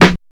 Clean Acoustic Snare Sound F Key 11.wav
Royality free snare sound tuned to the F note. Loudest frequency: 1171Hz
clean-acoustic-snare-sound-f-key-11-xOc.mp3